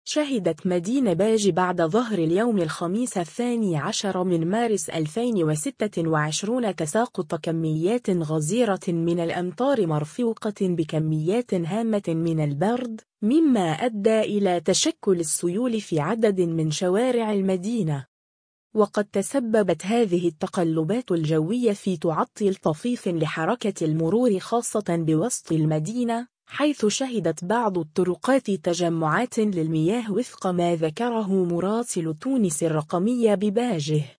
أمطار غزيرة وتساقط للبرد في باجة [فيديو]
شهدت مدينة باجة بعد ظهر اليوم الخميس 12 مارس 2026 تساقط كميات غزيرة من الأمطار مرفوقة بكميات هامة من البرد، مما أدى إلى تشكّل السيول في عدد من شوارع المدينة.